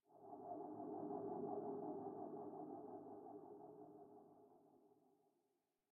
Minecraft Version Minecraft Version latest Latest Release | Latest Snapshot latest / assets / minecraft / sounds / ambient / underwater / additions / animal1.ogg Compare With Compare With Latest Release | Latest Snapshot
animal1.ogg